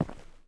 Terrarum/assets/mods/basegame/audio/effects/mining/ROCK.6.wav at 8a1ff32fa7cf78fa41ef29e28a1e4e70a76294ea
mining sounds
ROCK.6.wav